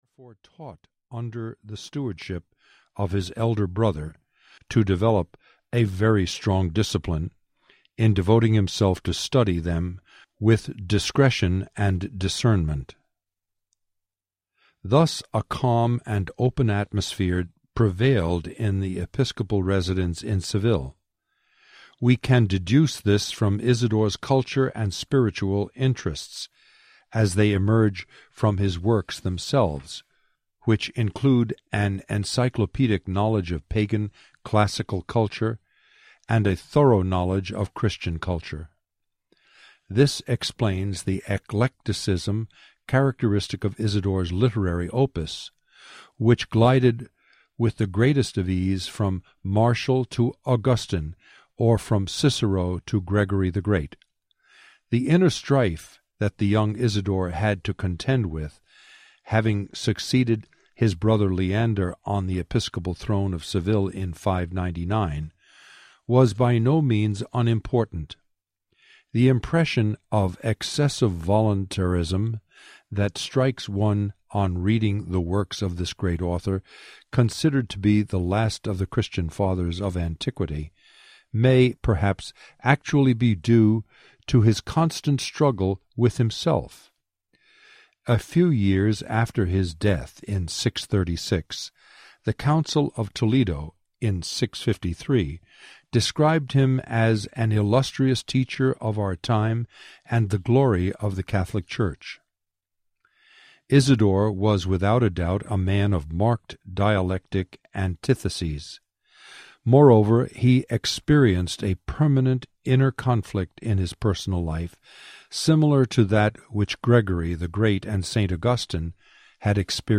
Holiness Is Always in Season Audiobook
12.15 Hrs. – Unabridged